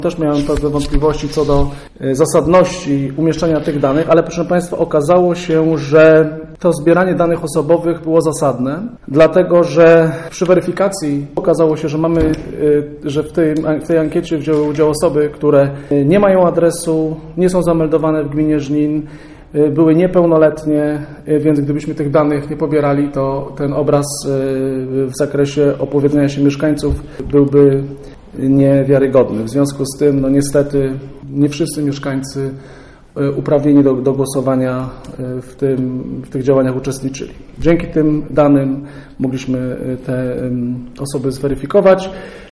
mówił Burmistrz Robert Luchowski.